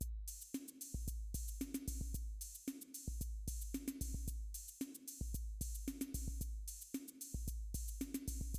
Drumstep Kit Loop 4 Clicky Added
描述：我的Drumstep Kit循环是用于dubstep或舞曲等的鼓循环， 鼓循环3，但有Clicky效果。
Tag: 150 bpm Dubstep Loops Drum Loops 1.45 MB wav Key : Unknown